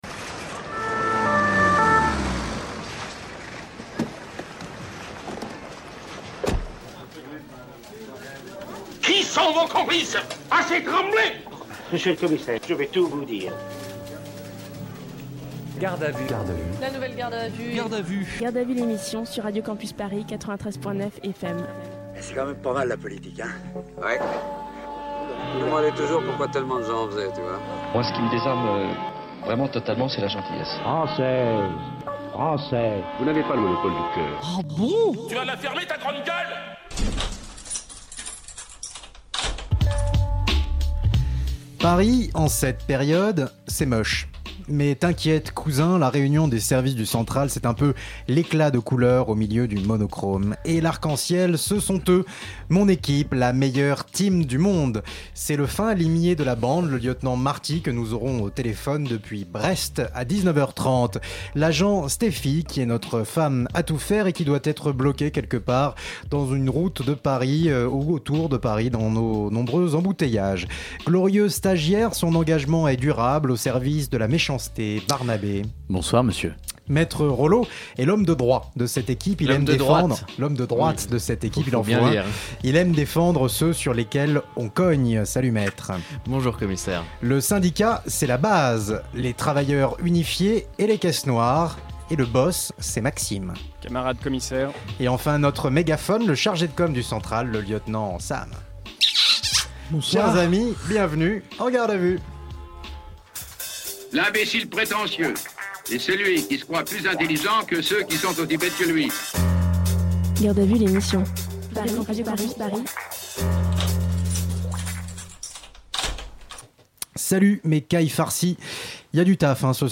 PS : pour des raisons techniques bien largement indépendantes de nos volontés combinées, cette émission a bénéficié d'un enregistrement d'une durée limitée à 32 minutes.